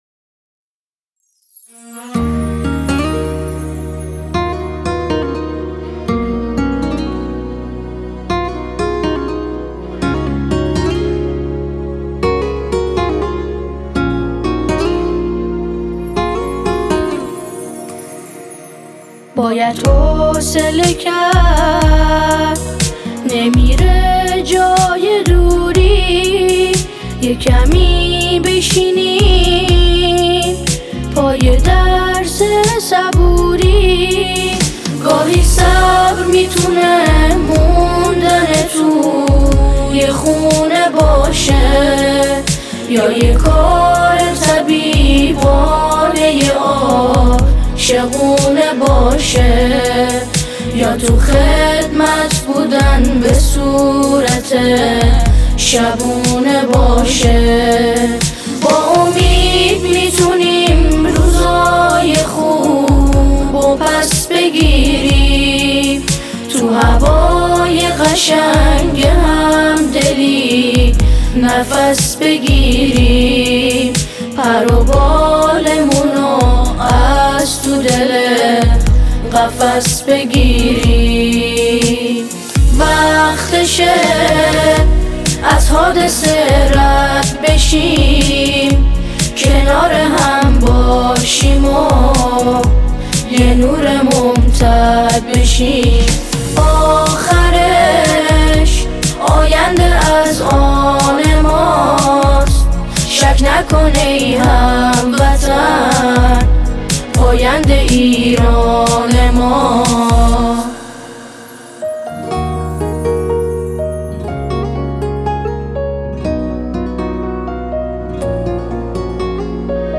قطعه سرود